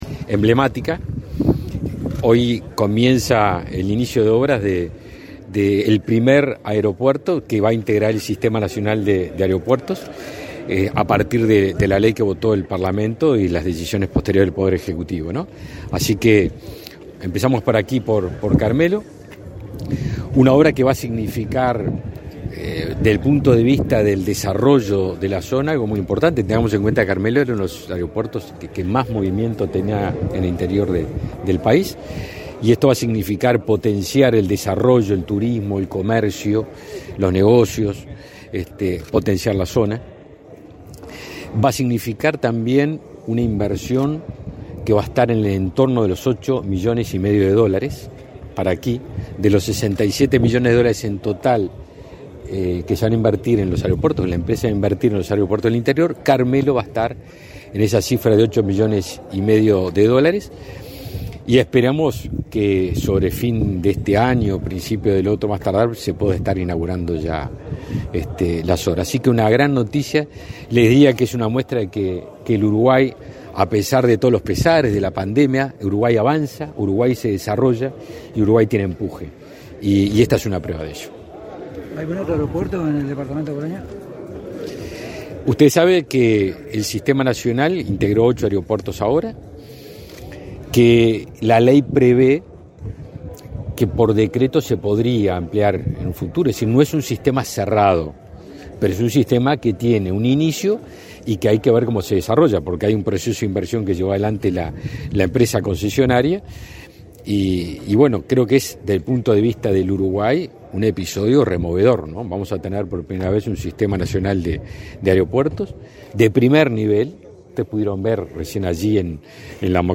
Declaraciones a la prensa del ministro de Defensa, Javier García